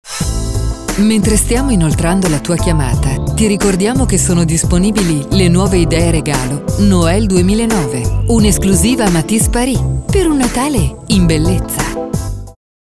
segreterie per risponditori telefonici
Risponditore Matis Paris
segreteria_tel.mp3